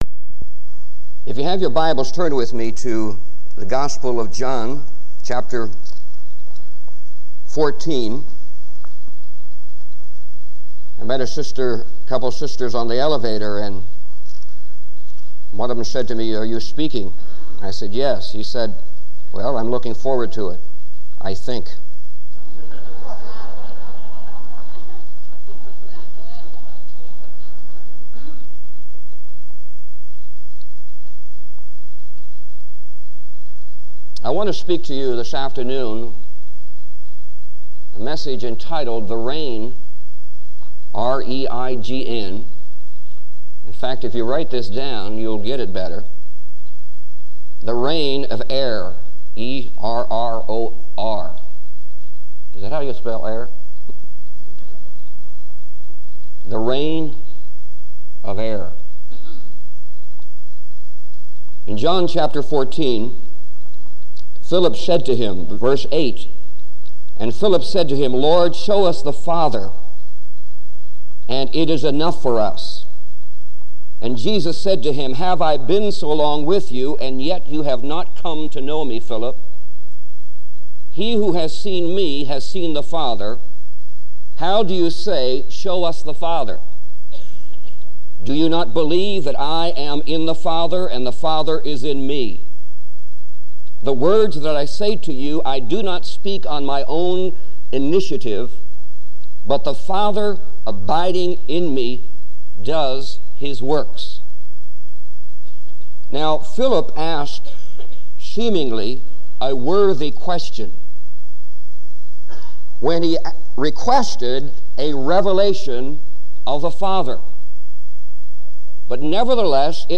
This teaching challenges the church to move beyond superficial faith and embrace the full responsibility of living in God's family.